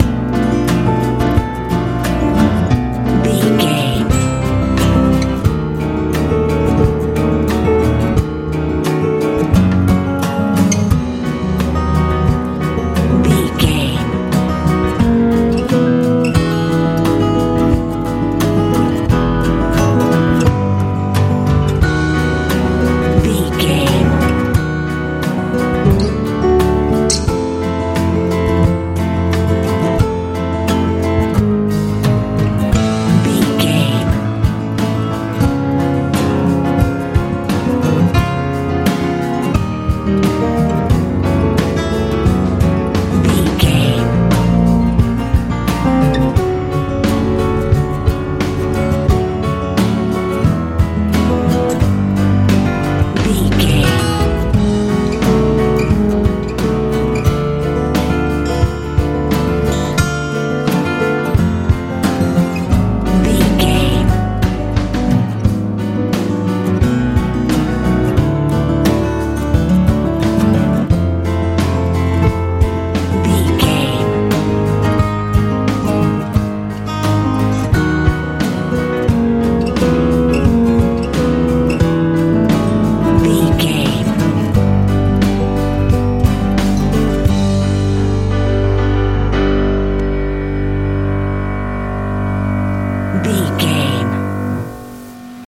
pop ballad
Aeolian/Minor
calm
mellow
acoustic guitar
piano
bass guitar
drums